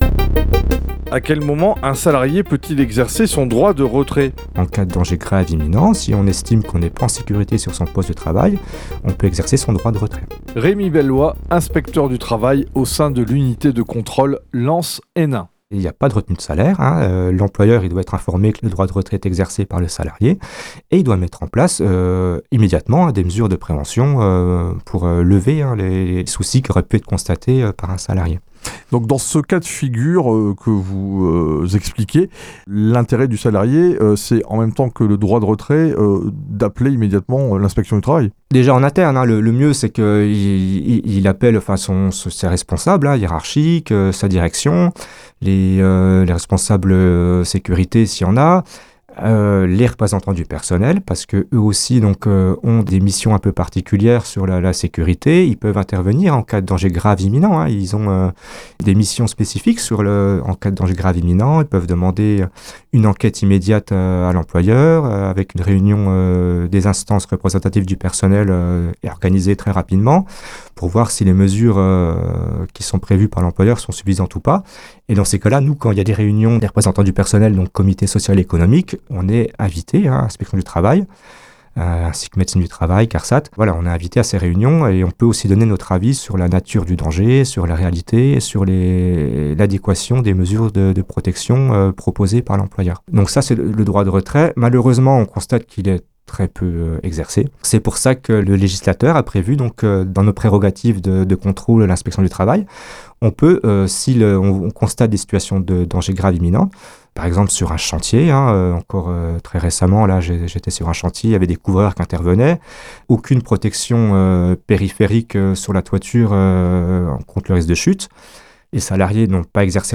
8 spots radio diffusés à l’automne 2025 :